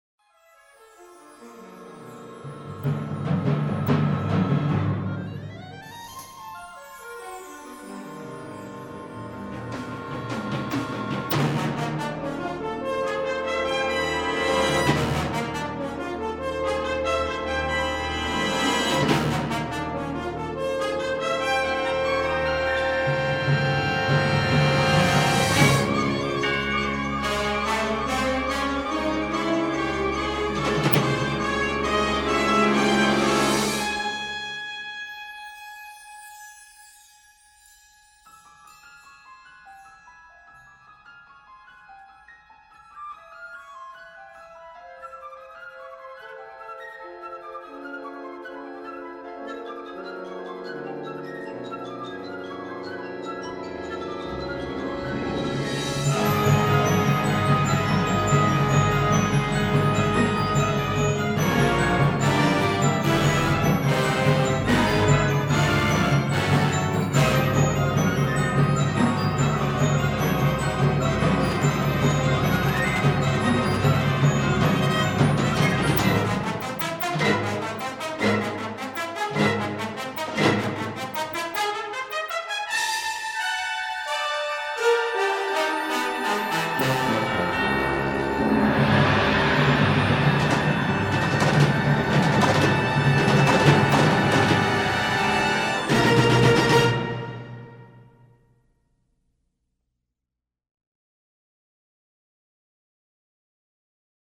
Symphonic Band